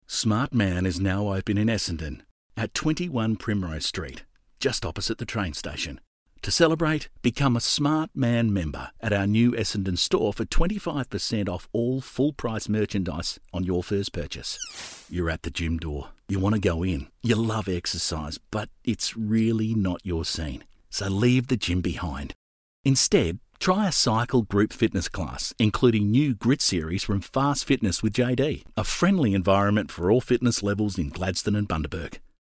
I guess you can call me ‘the everyday bloke’.
• Corporate Friendly